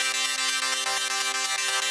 SaS_MovingPad01_125-C.wav